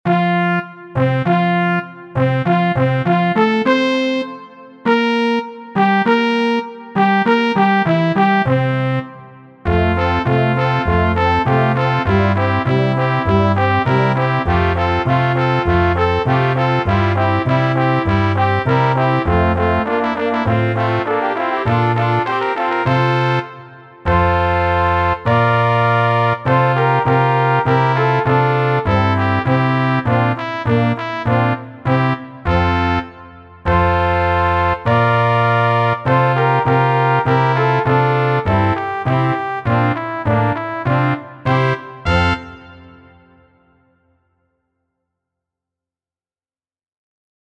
5.G        Dreistimmigkeit in G-Dur